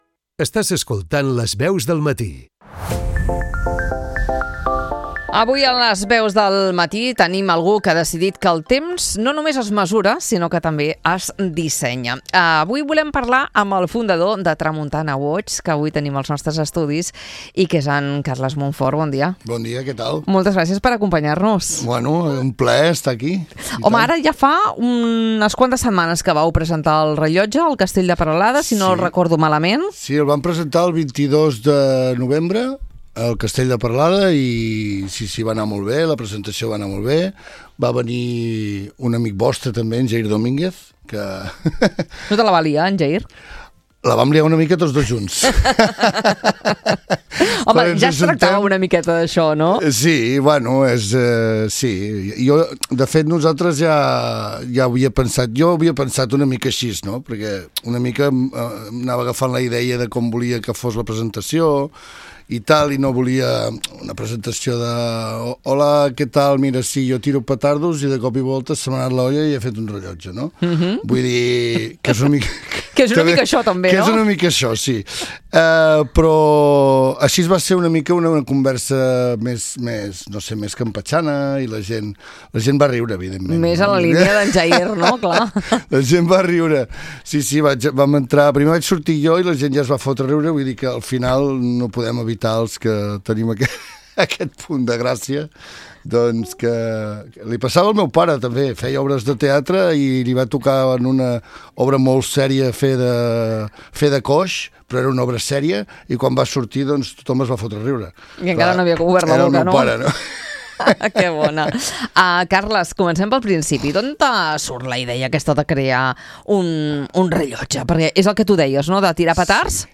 Una conversa inspiradora sobre emprenedoria, passió i el poder de transformar una idea en un objecte que explica una història.